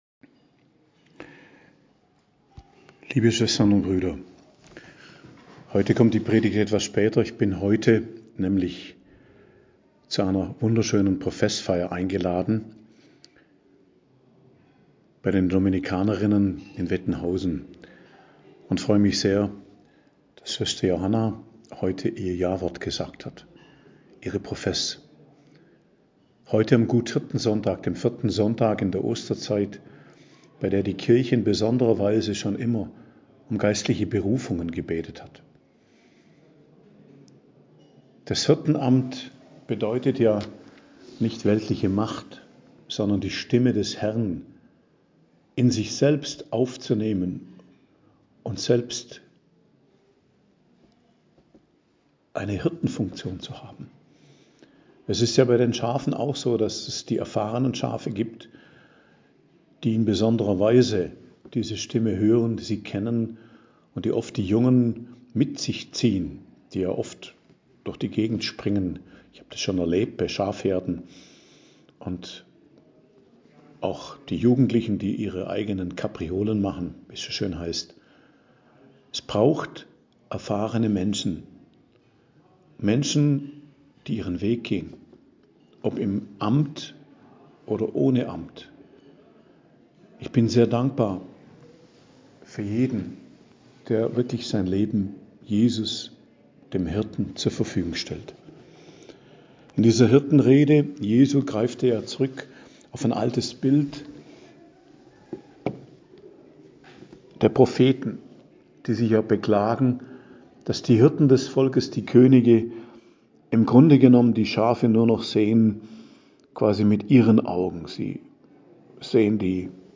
Predigt zum 4. Sonntag der Osterzeit, 21.04.2024